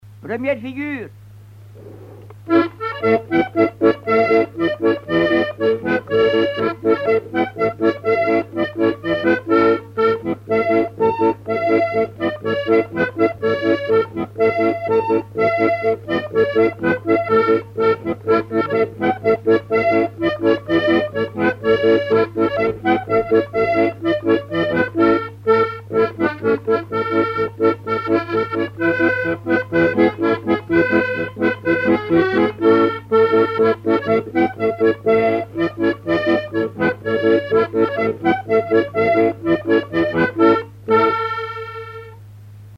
Quadrille
danse : quadrille
Alouette FM numérisation d'émissions par EthnoDoc
Pièce musicale inédite